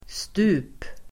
Uttal: [stu:p]